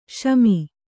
♪ śami